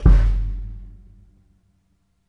drums and loops » high snare
描述：snare drum
标签： big drum hit kit loud single snare
声道立体声